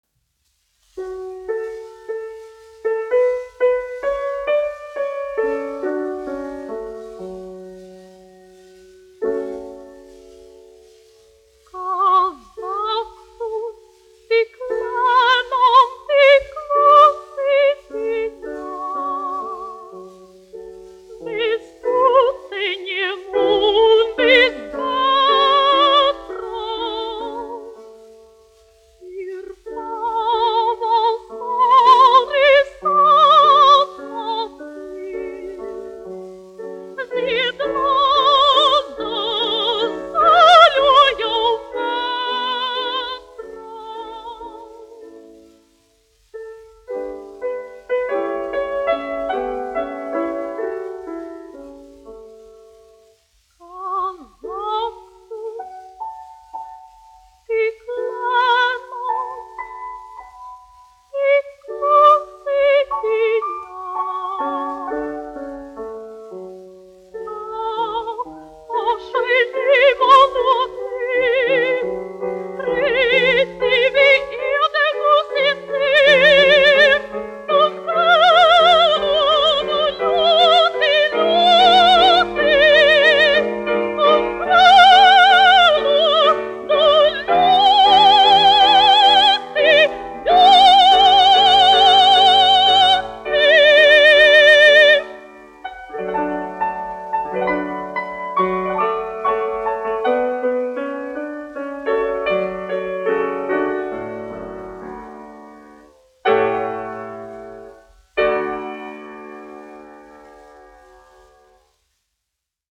Žermēna Heine-Vāgnere, 1923-2017, dziedātājs
1 skpl. : analogs, 78 apgr/min, mono ; 25 cm
Dziesmas (augsta balss) ar klavierēm
Latvijas vēsturiskie šellaka skaņuplašu ieraksti (Kolekcija)